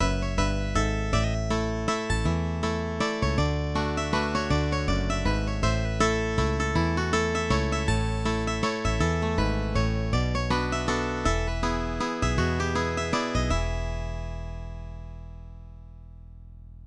Gospel - 3/4 time